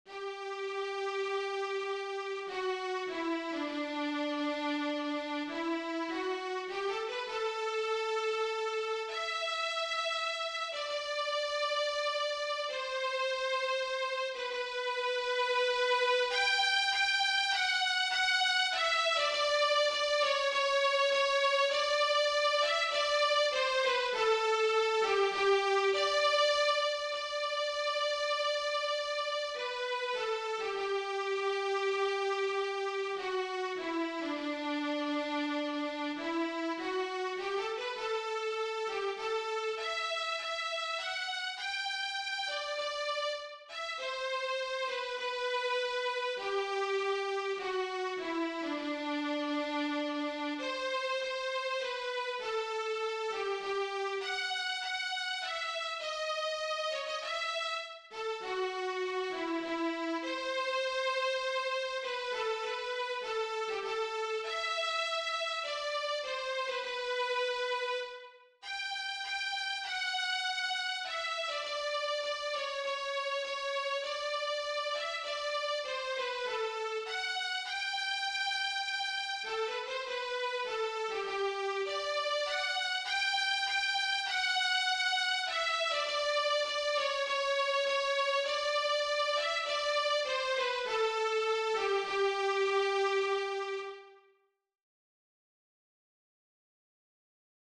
DIGITAL SHEET MUSIC - VIOLIN SOLO
Baroque